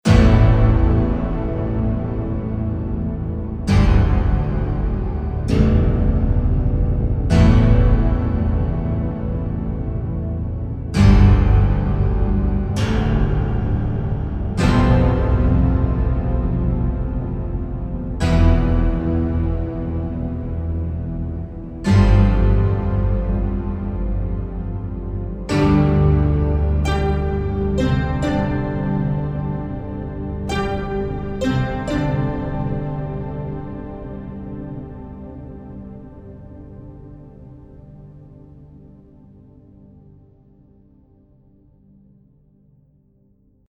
Das Clavichord ist vom Klang her dem Cembalo verwandt und wurde erstmals um 1400 gebaut.
Es geht aber auch bombastisch mit dem Patch Clavichord – Epic Cinema: